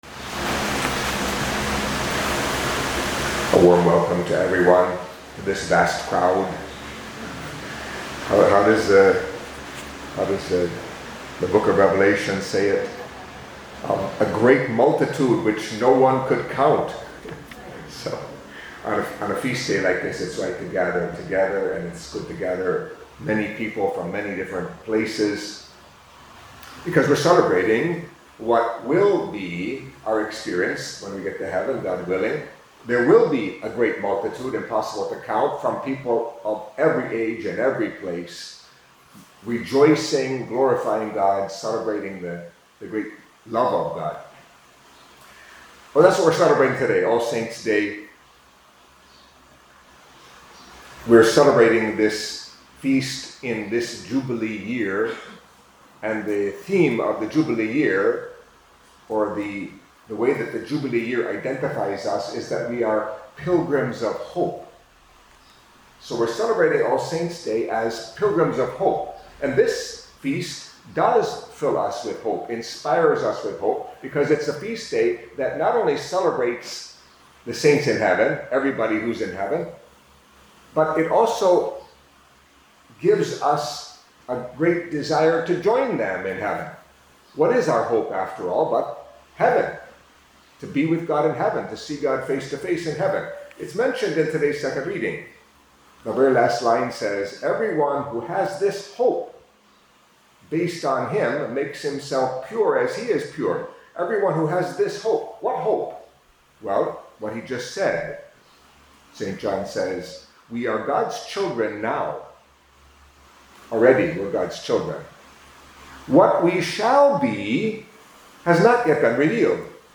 Catholic Mass homily for Solemnity of All Saints